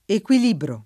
vai all'elenco alfabetico delle voci ingrandisci il carattere 100% rimpicciolisci il carattere stampa invia tramite posta elettronica codividi su Facebook equilibrare v.; equilibro [ ek U il & bro ] — non equilibriare